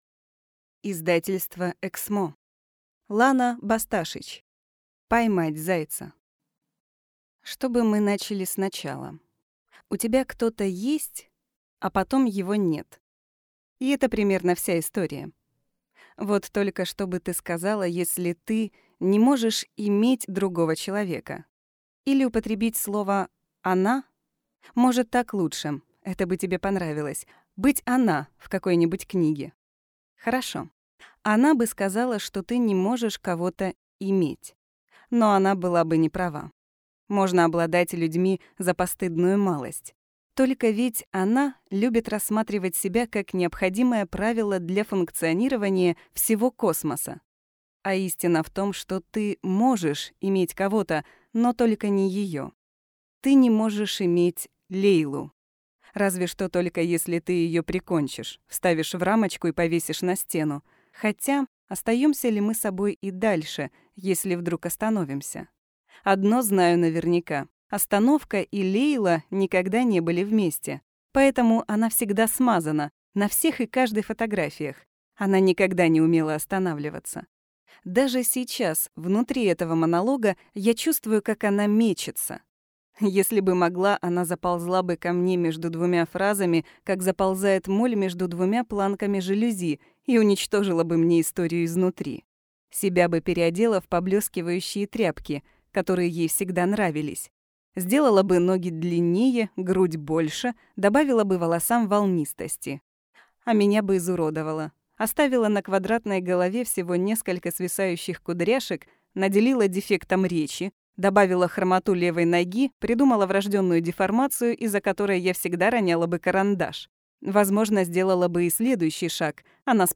Аудиокнига Поймать зайца | Библиотека аудиокниг